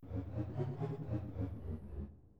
photoRise.wav